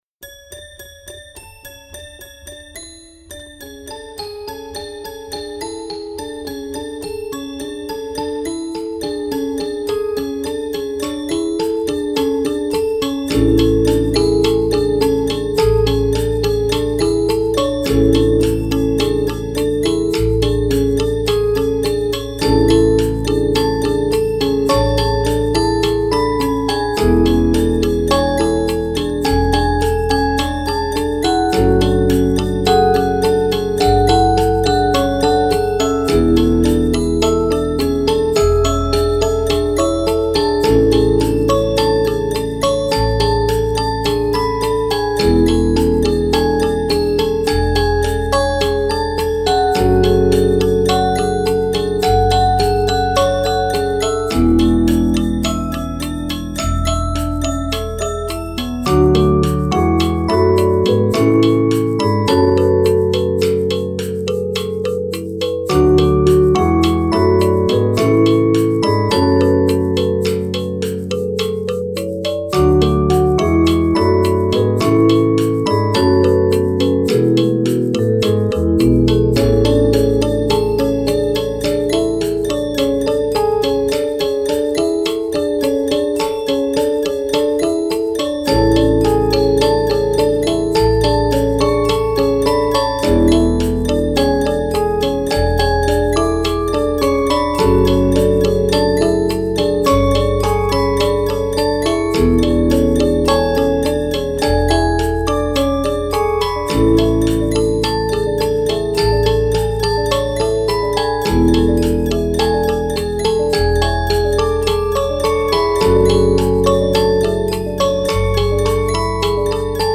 Genre: Instrumental, Music for Children